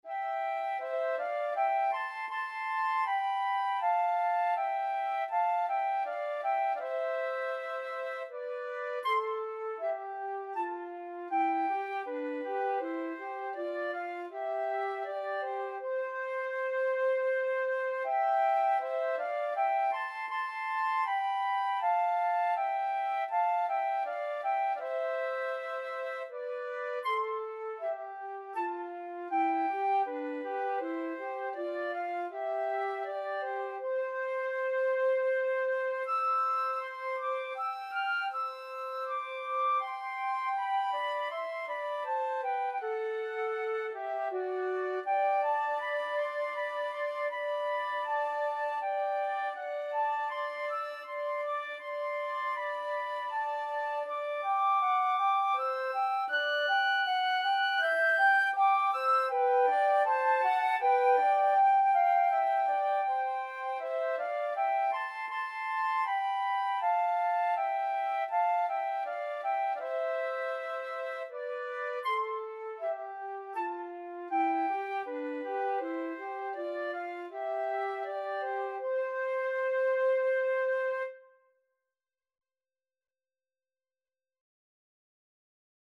Flute 1Flute 2
Andante
3/4 (View more 3/4 Music)
Classical (View more Classical Flute Duet Music)